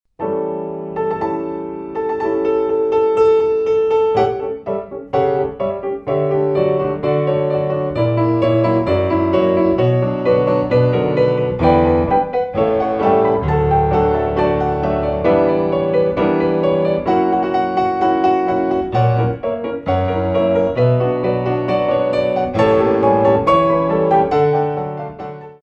Coda ou Fouettés Manége